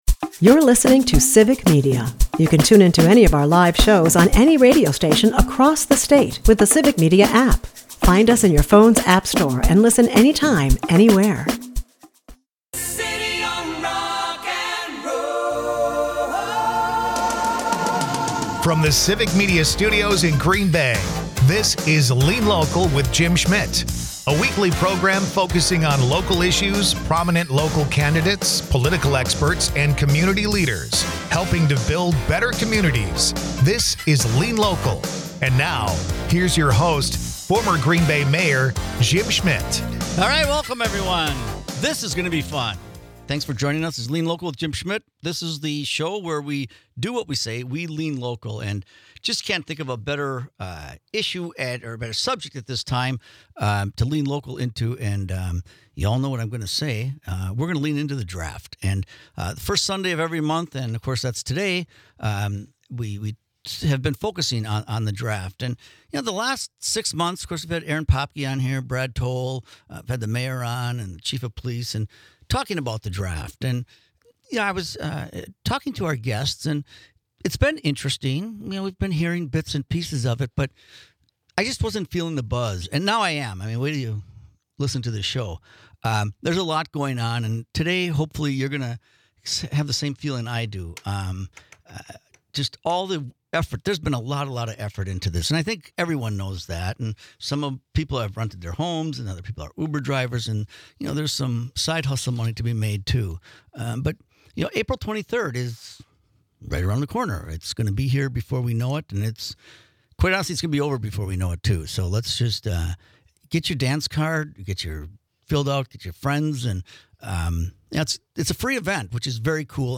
Hotel Northland talks about tours running for the draft. Lean Local is a part of the Civic Media radio network and airs Sunday's from 1-2 PM on WGBW .
Dive into the heart of community issues with 'Lean Local,' hosted by former Green Bay Mayor Jim Schmitt.